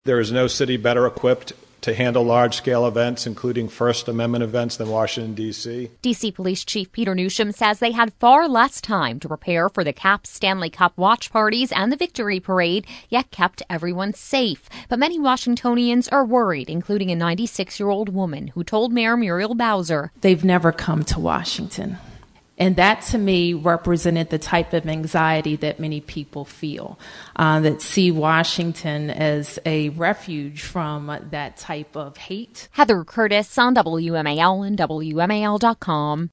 WASHINGTON (WMAL) – Safety concerns are on everyone’s minds as the Unite the Right rally nears, but D.C. officials said at a press conference Thursday they are ready to keep people safe.
“There is no city better equipped to handle large-scale events, including first amendment events than Washington, D.C.,” said D.C. Police Chief Peter Newsham.